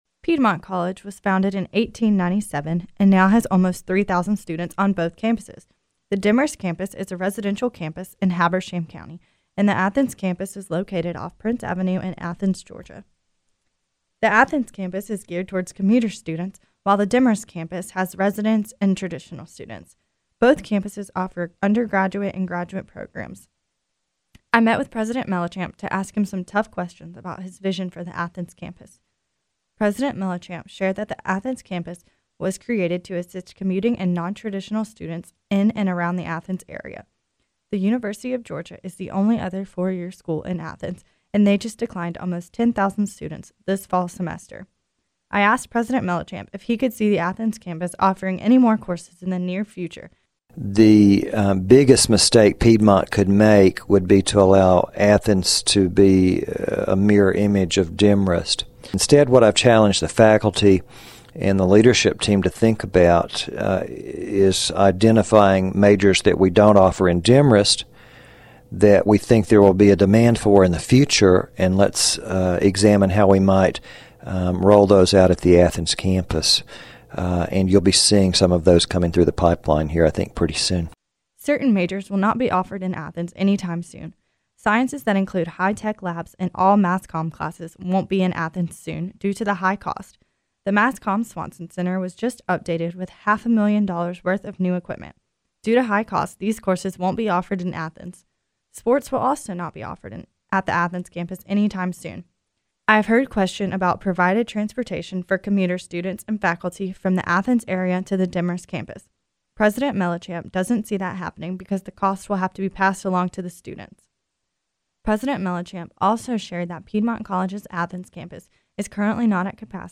Student reporter